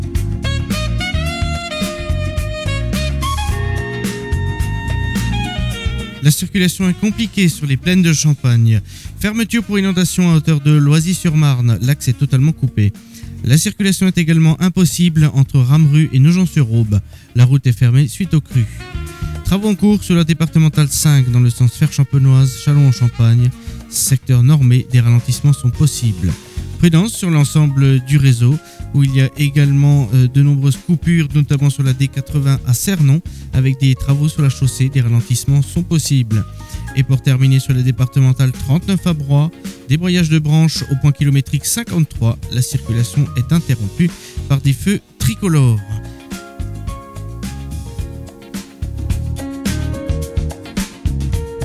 Bienvenue dans l’InfoRoute des Plaines – votre bulletin circulation du matin !